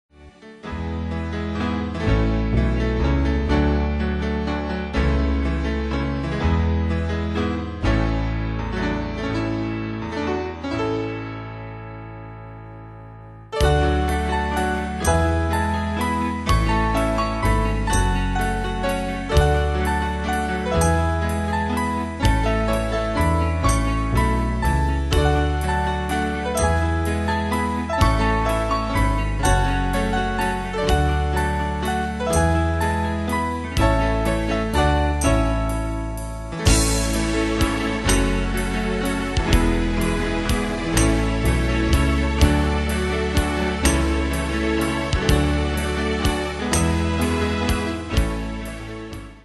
Danse/Dance: Folklore Cat Id.
Pro Backing Tracks